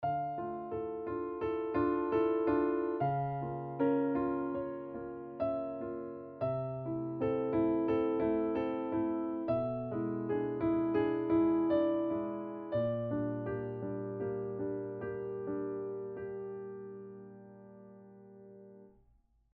Идут щелчки при проигрывании партий с включённым темпо-треком.